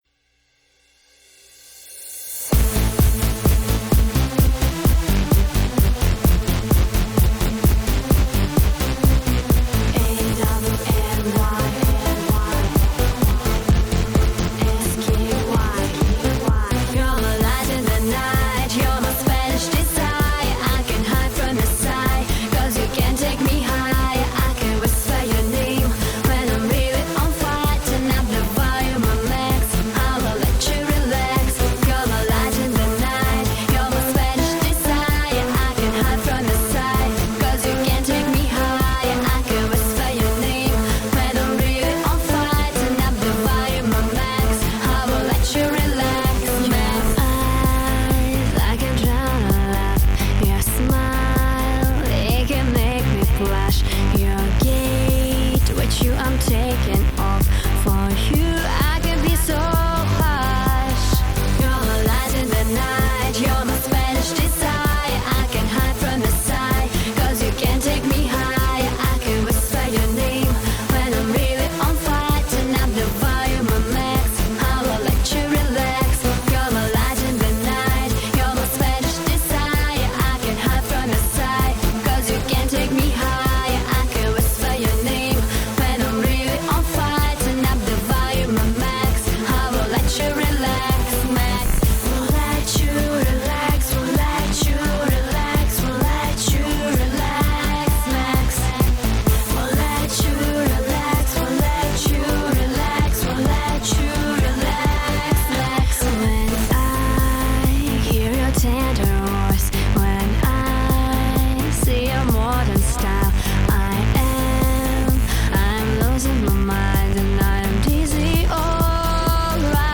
Dance, electronic, female vocals. 1.8 MB.